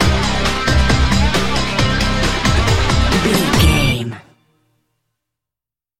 Aeolian/Minor
hard rock
lead guitar
bass
drums
aggressive
energetic
intense
nu metal
alternative metal